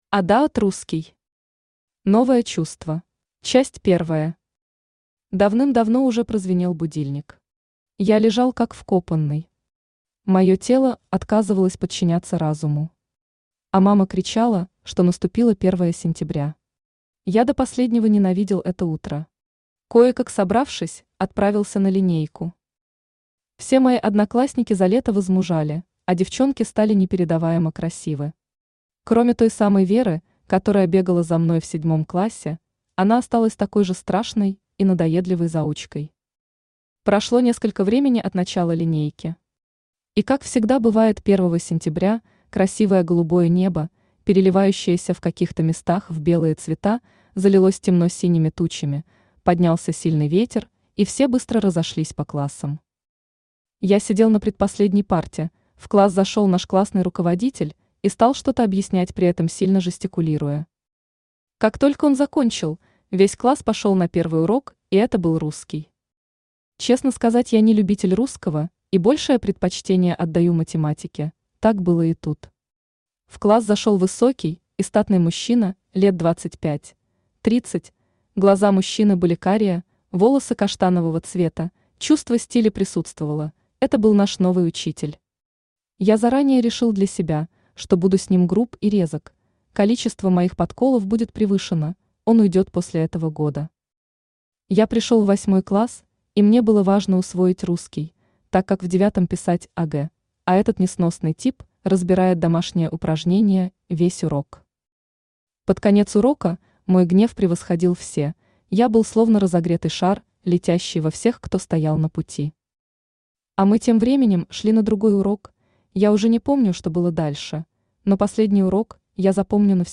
Аудиокнига Новое чувство | Библиотека аудиокниг
Aудиокнига Новое чувство Автор иDиоt Русский Читает аудиокнигу Авточтец ЛитРес.